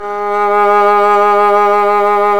Index of /90_sSampleCDs/Roland - String Master Series/STR_Violin 1 vb/STR_Vln1 % marc